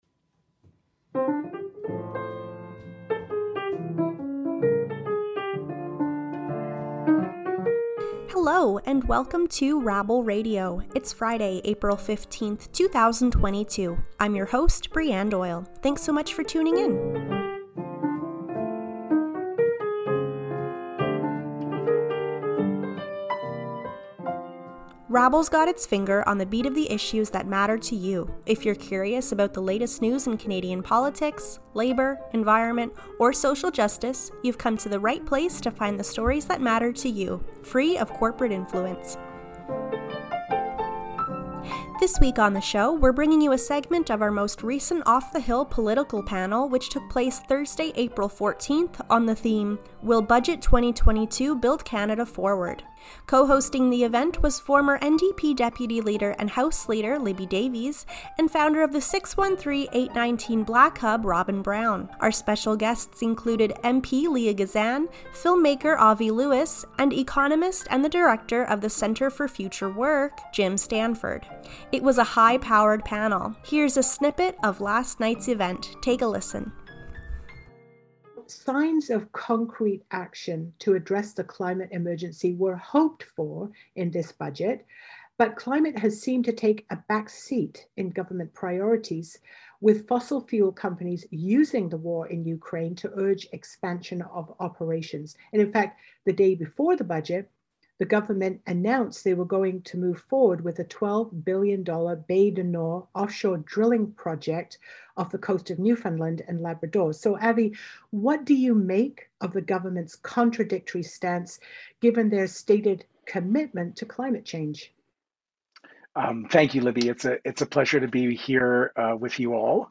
Our panelists discuss the recently released federal budget.